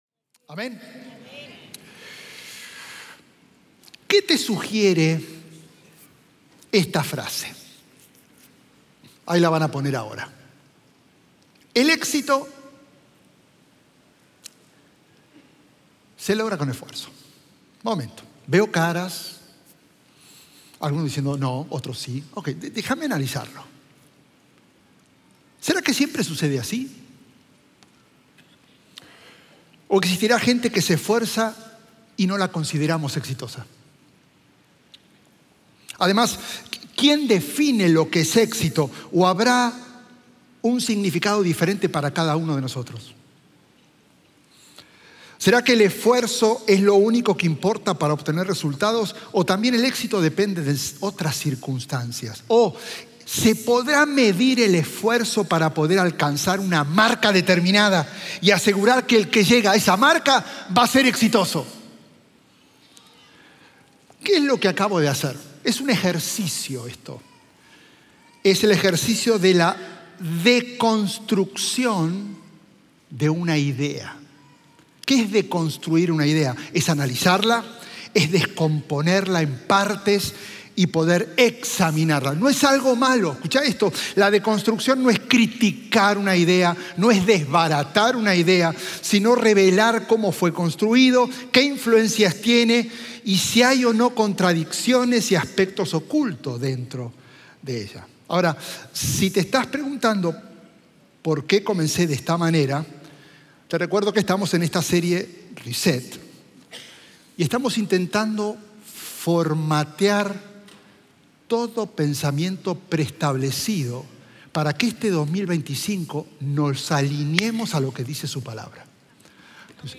Un mensaje de la serie "Reset ."